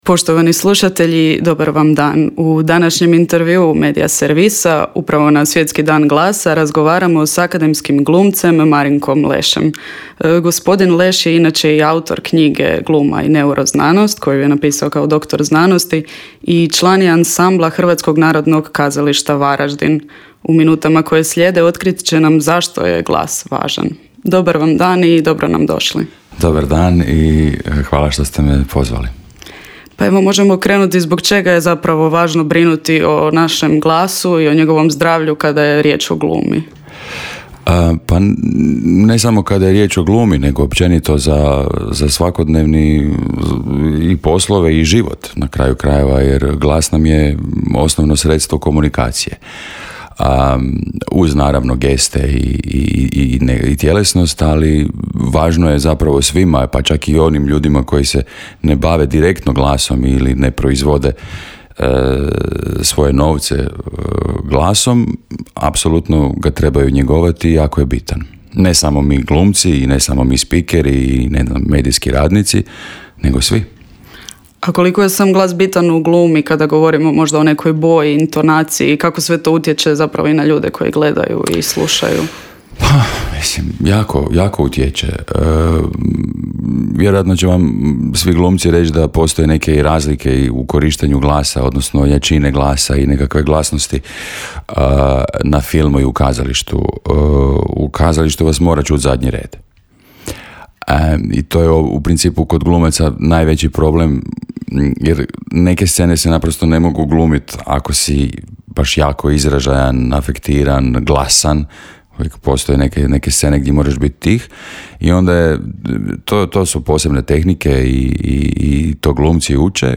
pa smo u Intervju Media servisa ugostili našeg radijskog kolegu